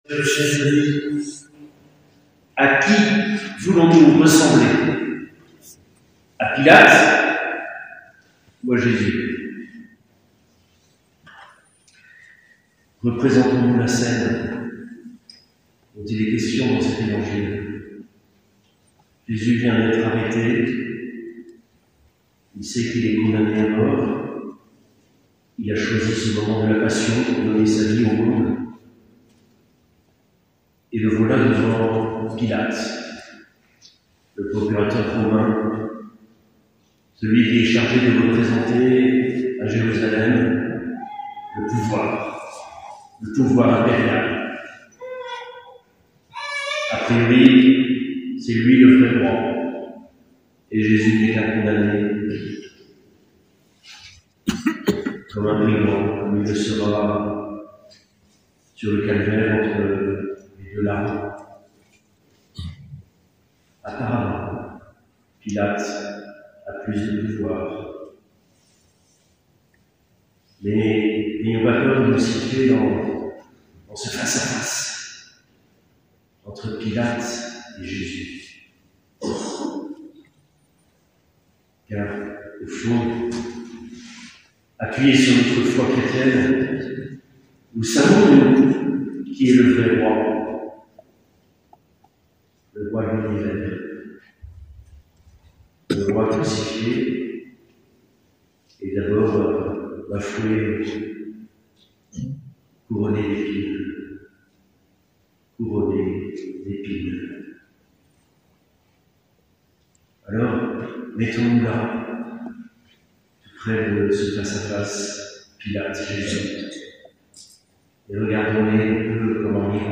Mgr Olivier de Cagny Evêque d’Evreux